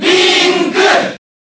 File:Link Cheer Japanese SSBB.ogg
Link_Cheer_Japanese_SSBB.ogg